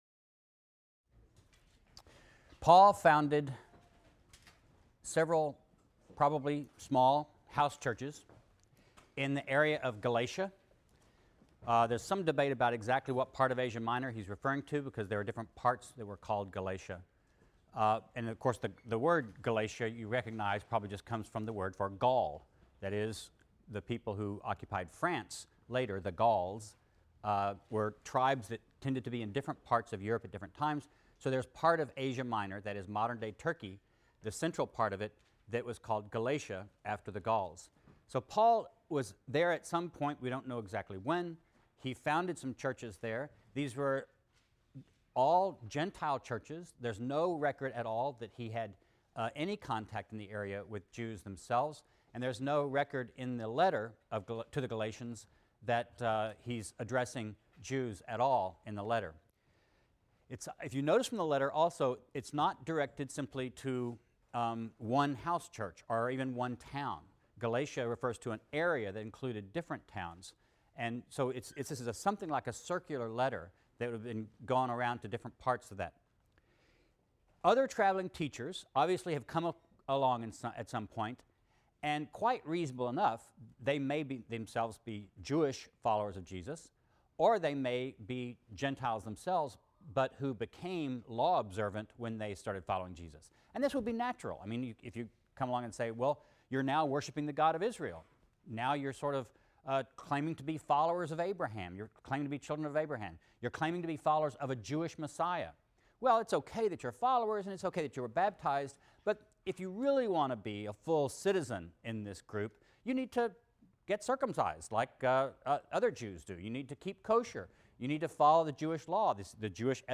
RLST 152 - Lecture 16 - Paul as Jewish Theologian | Open Yale Courses